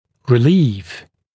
[rɪ’liːf][ри’ли:ф]облегчение, ослабление, уменьшение (напр. скученности)